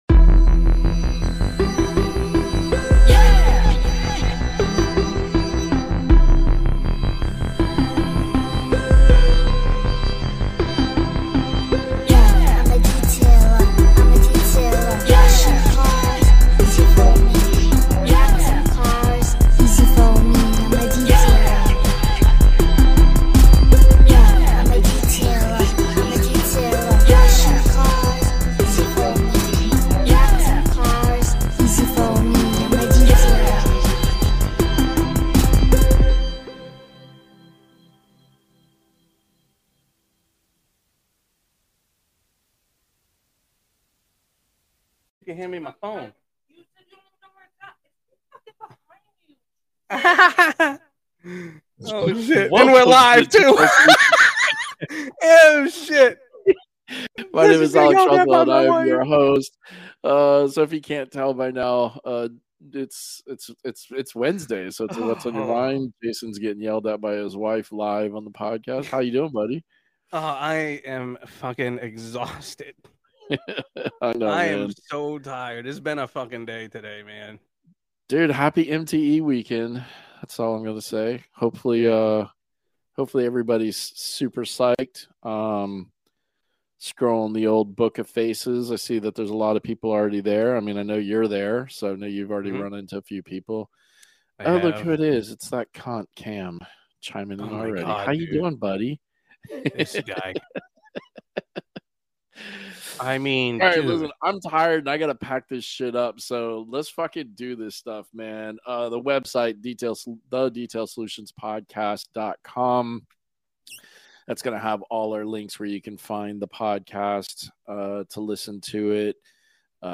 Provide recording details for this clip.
Facebook and YouTube live call-in shows as we prepare for the first expo of the year, MTE. Also we chat about History channel's new HIT series Dirty Old Cars.